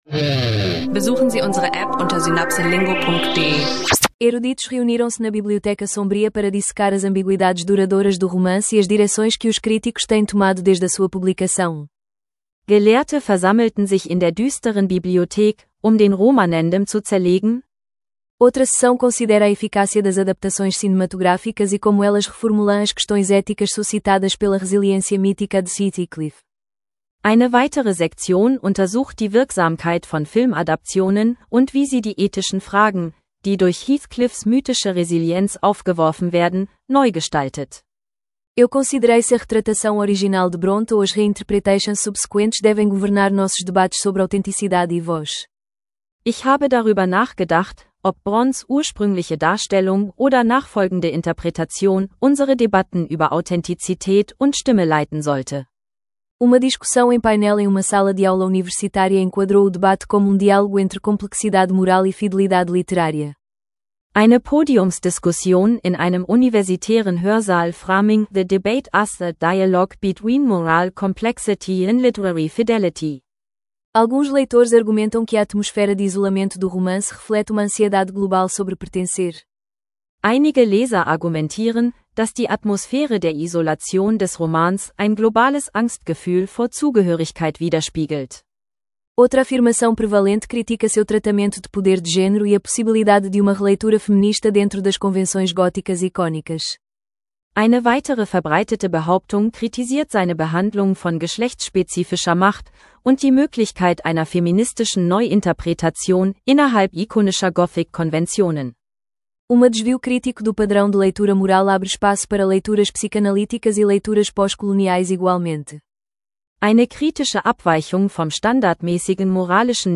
Eine KI-gestützte, interaktive Lernfolge über Wuthering Heights – Portugiesisch lernen leicht gemacht mit Debatten, Authentizität und literarischer Stimme.